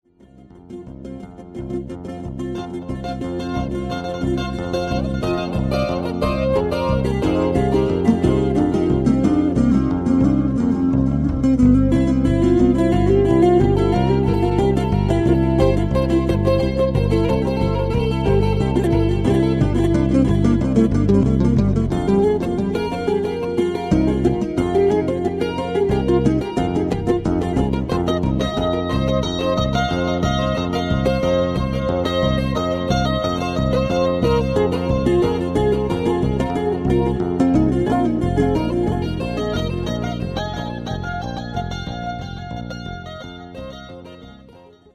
No loops, just tapping delay.